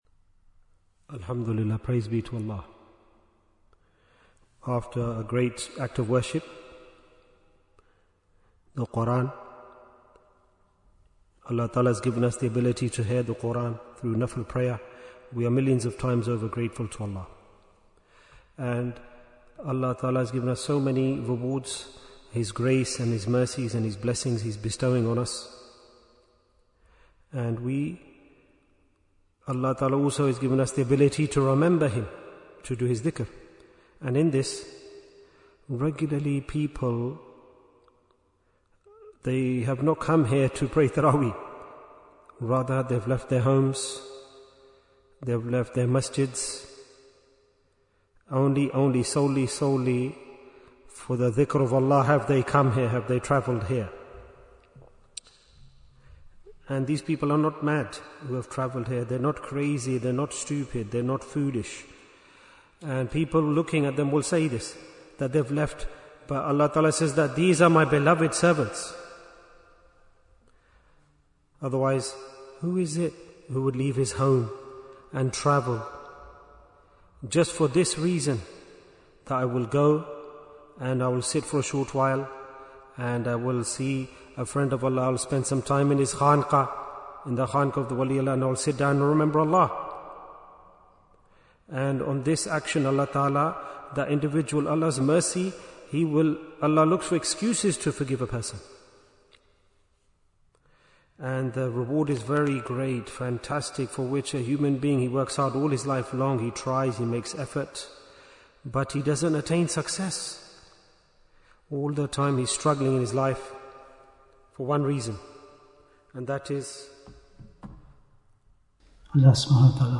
Jewels of Ramadhan 2026 - Episode 34 Bayan, 48 minutes14th March, 2026